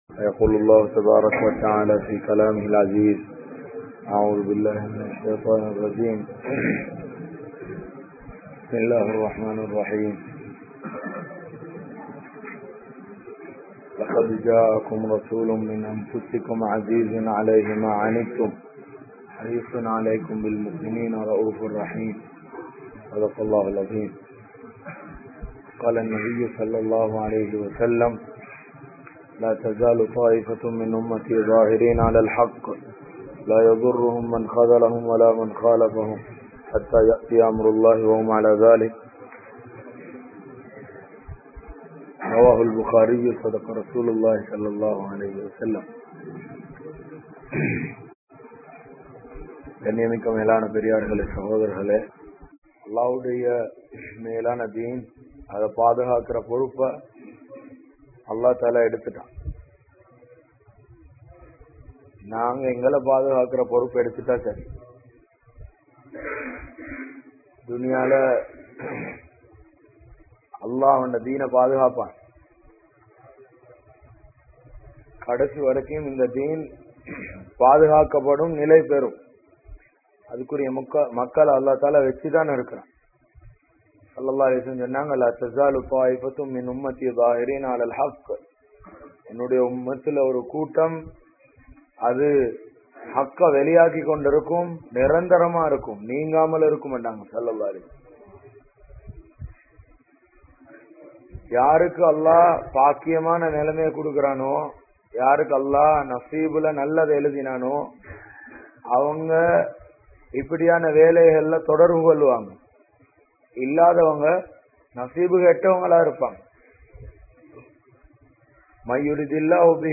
Al Quraan and Hadhees Oliyil Dheenudaiya Ulaippu(அல்குர்ஆன் மற்றும் ஹதீஸ் ஒளியில் தீனுடைய உழைப்பு) | Audio Bayans | All Ceylon Muslim Youth Community | Addalaichenai
Mafaaza Masjith